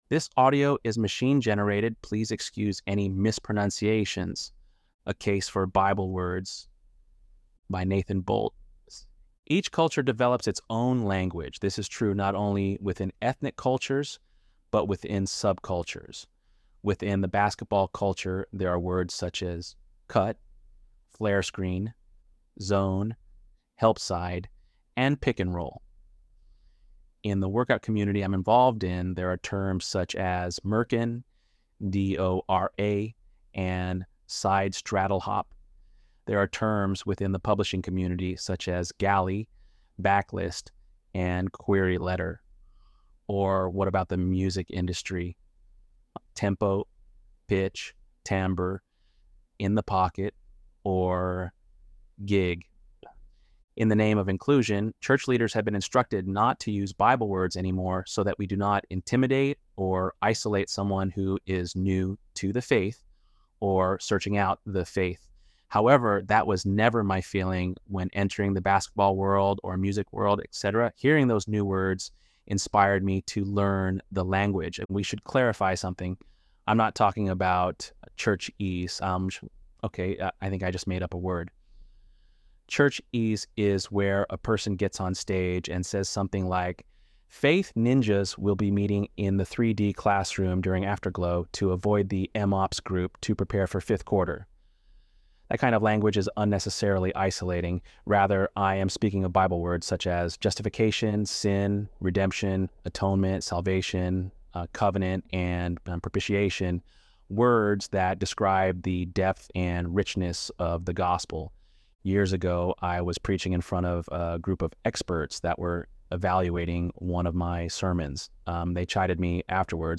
ElevenLabs_7.15_Bible_Words.mp3